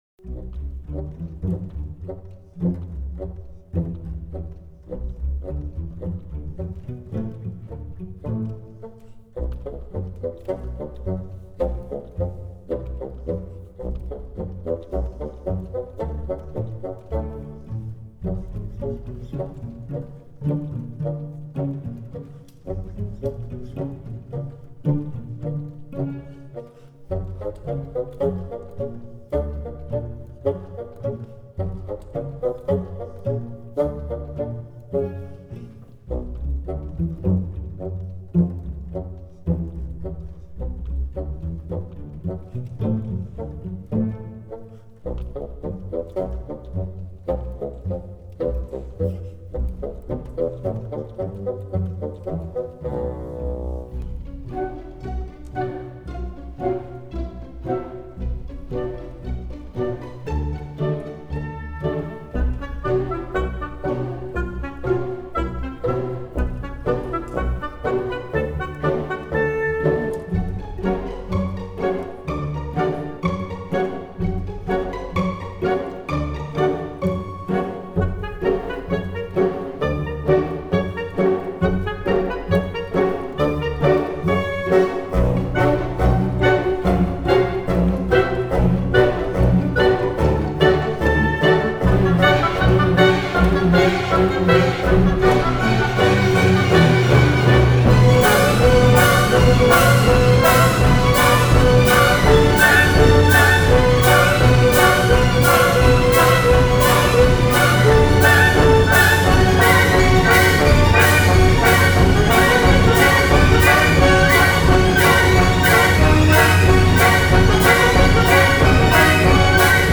Фоновая интригующая музыка для крутой сцены